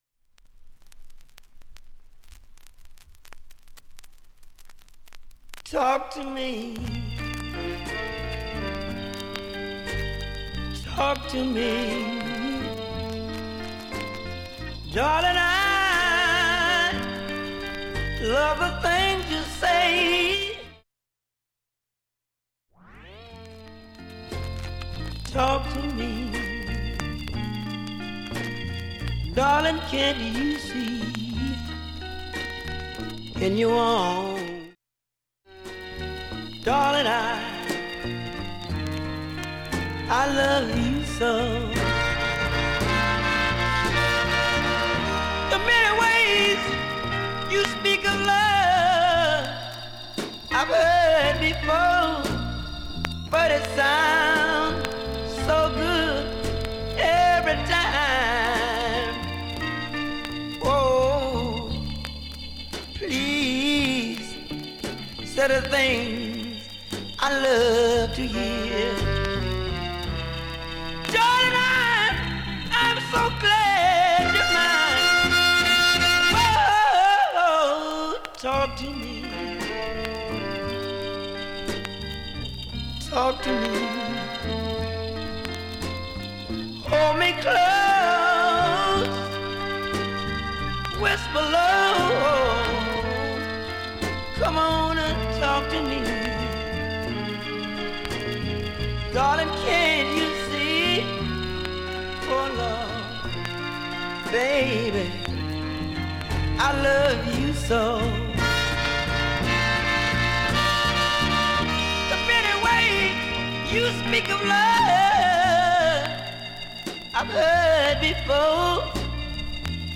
盤面きれいで音質良好全曲試聴済み。
1,A-1始めかすかなプツが５回と３回出ます。
270秒の間に周回プツ出ますがかすかです。
3,(5m10s〜)B-1始めかるいチリと４回プツ
５回までのかすかなプツが２箇所
３回までのかすかなプツが４箇所
単発のかすかなプツが７箇所